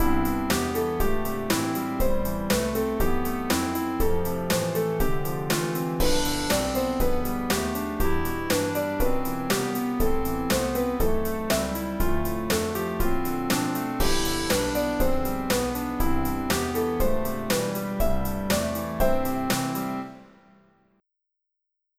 Add a crash on beat 1 of every 4th measure.
Drum track added with rock beat
Result: Drum track with kick, snare, hi-hat pattern and crash accents
piano_drum.wav